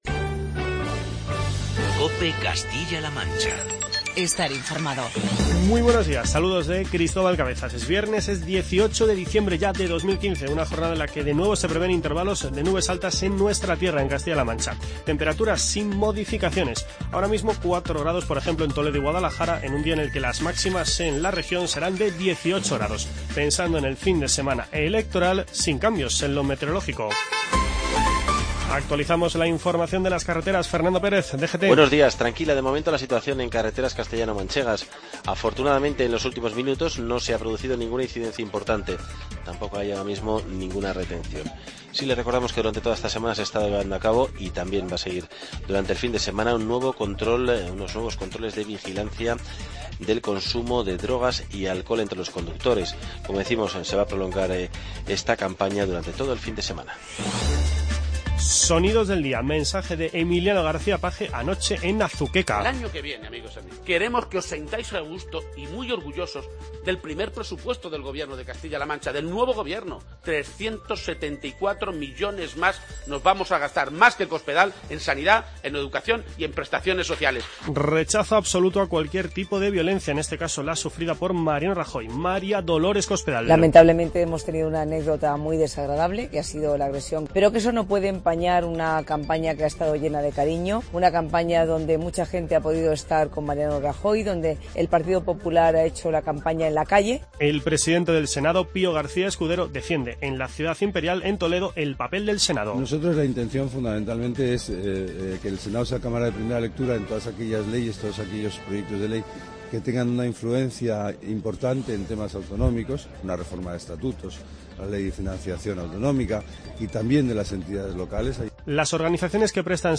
Informativo regional y provincial
Hoy con Emiliano García-Page, María Dolores Cospedal y Pío García-Escudero, entre otros testimonios.